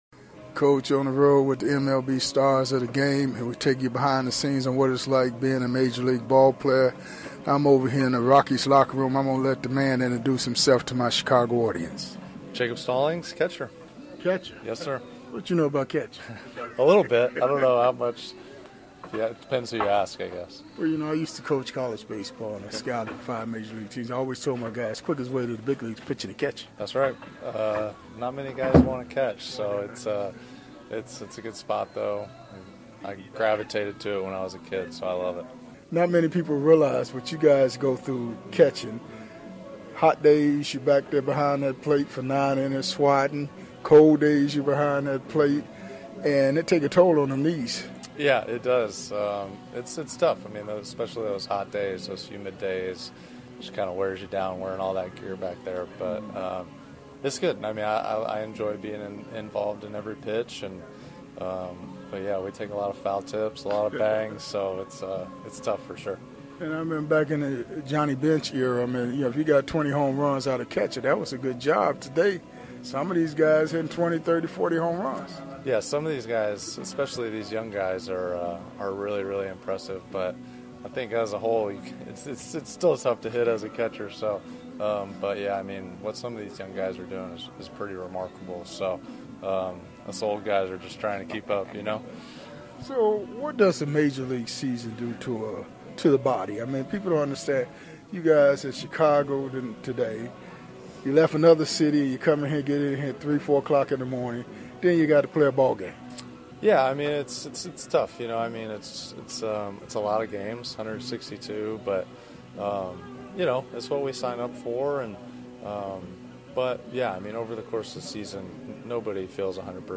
MLB Classic Interviews